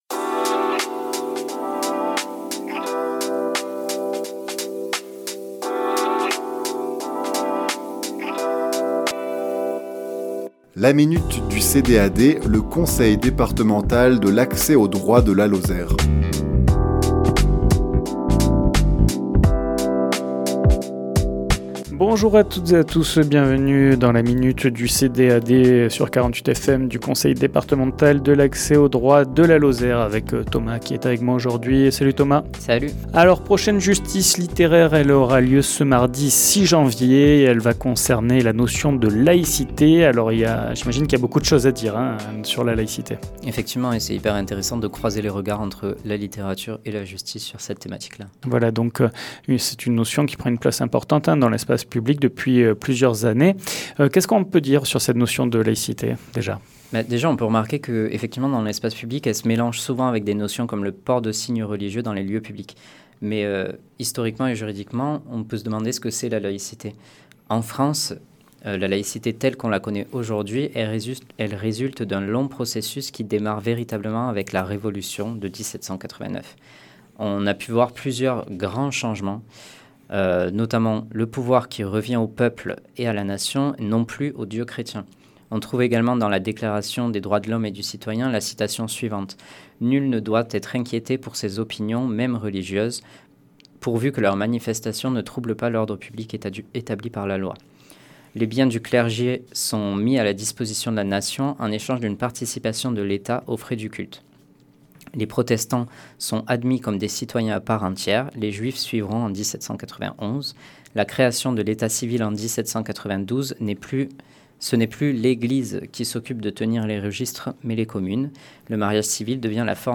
Chronique diffusée le lundi 29 décembre à 11h00 et 17h10